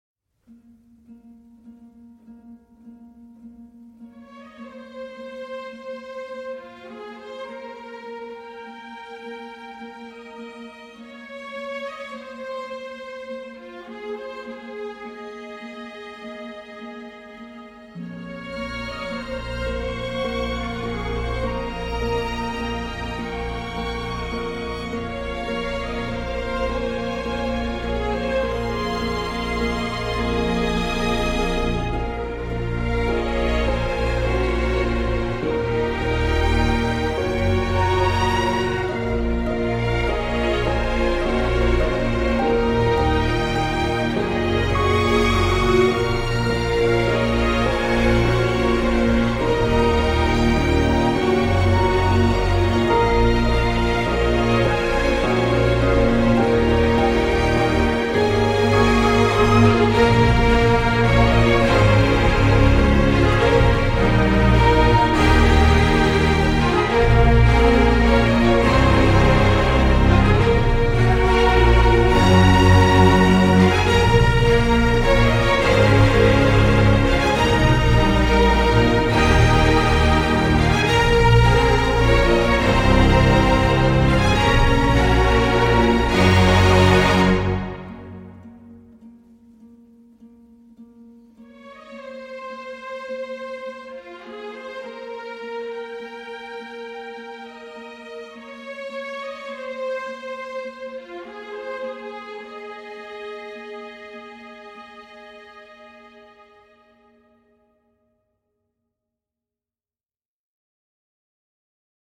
Une approche générale martiale et cuivrée